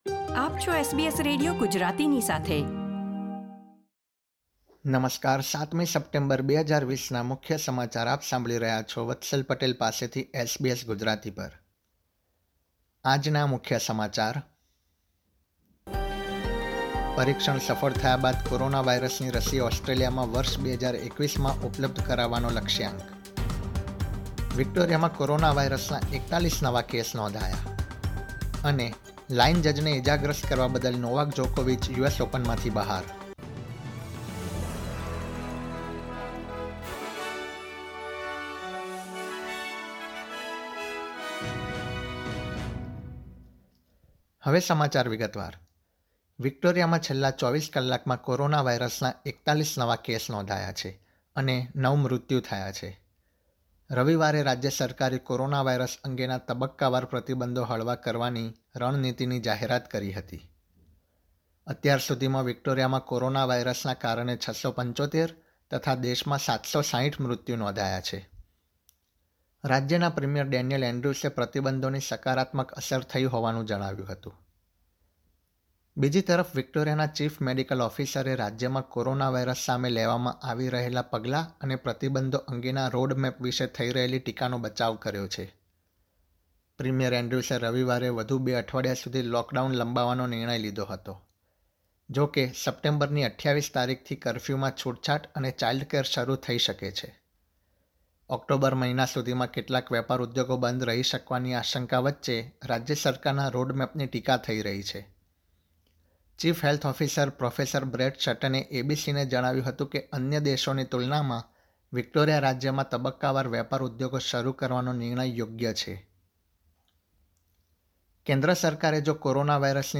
SBS Gujarati News Bulletin 7 September 2020
gujarati_0709_newsbulletin.mp3